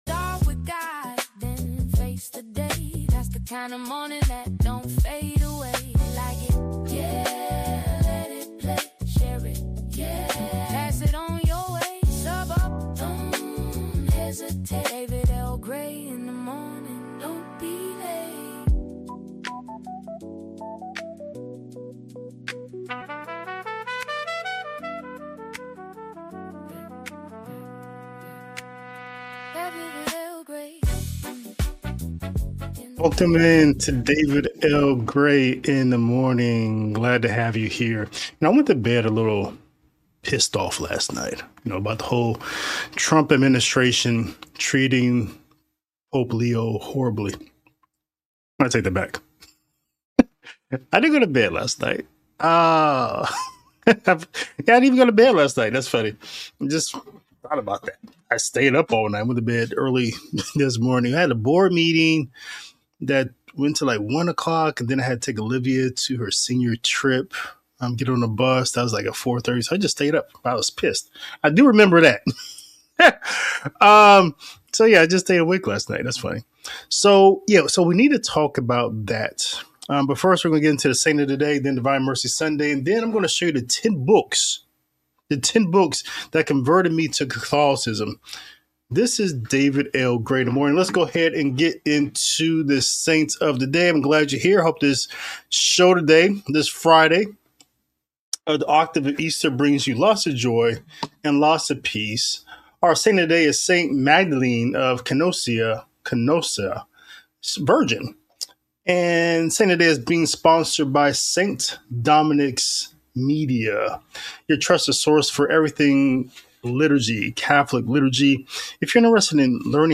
A tense morning show diving into Saint Magdalene of Canossa’s witness of charity, the meaning of Divine Mercy Sunday, new reports of Pentagon pressure on the Vatican, and the ten books that led to my conversion to Catholicism. Faith, controversy, and conversion collide in today’s broadcast.